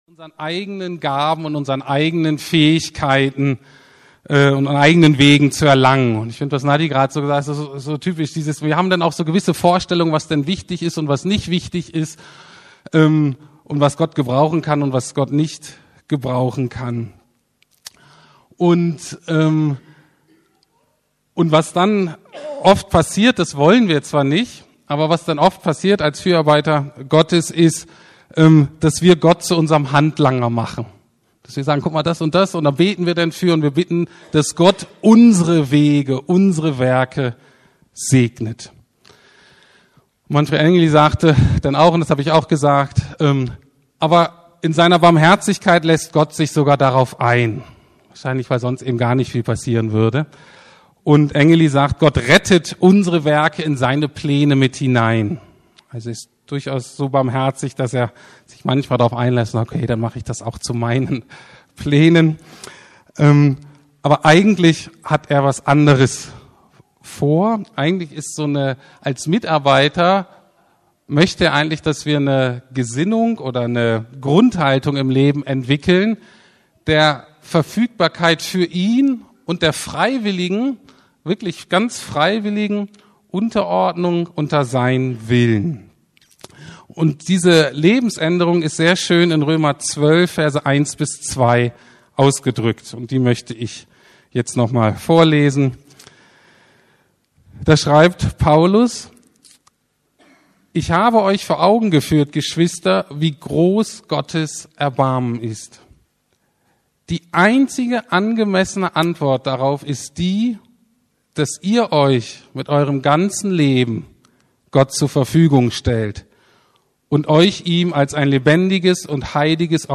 Neujahrsgottesdienst 2Teil